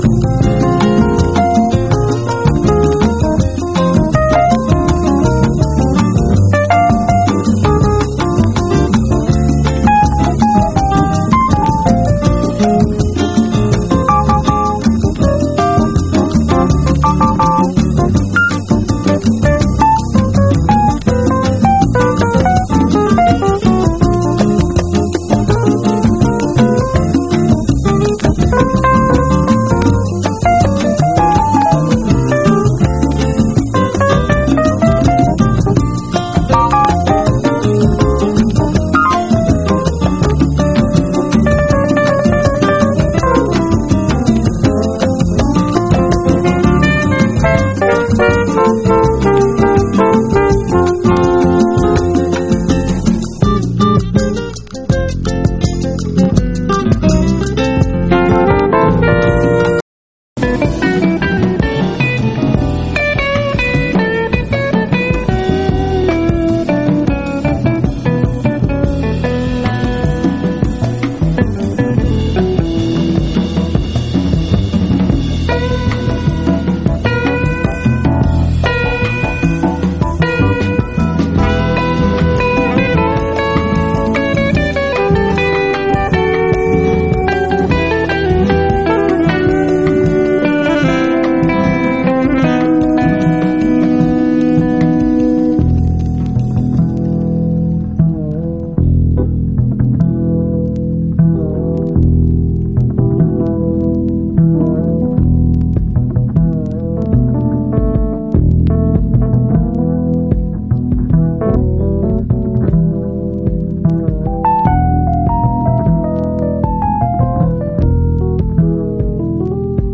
ROCK / 80'S/NEW WAVE. / NEW WAVE / WORLD / POLKA
泣きのメロディーでDJユースな一曲！
豊かな音楽性に溢れた素晴らしいアルバム！